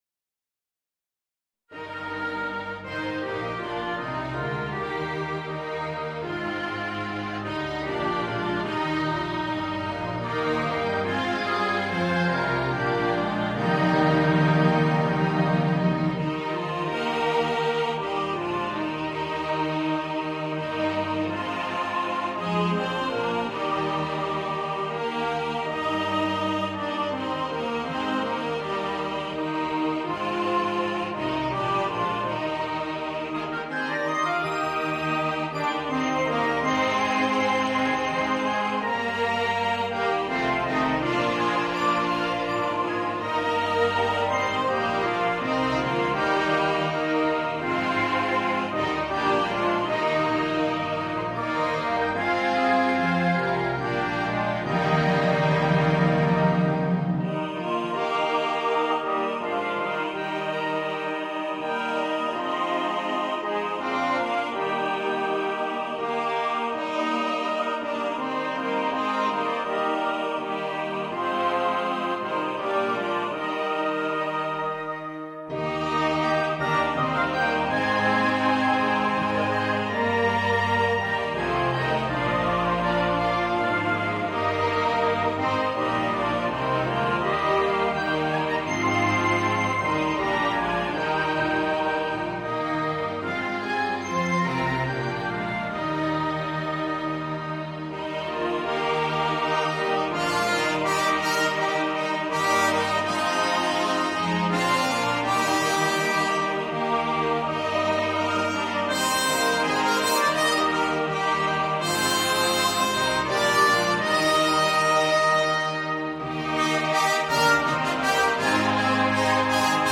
The tune is in 3/4 time.
There is a key change in the last verse (Eb to F).
The MP3 was recorded with NotePerformer 3.
Choral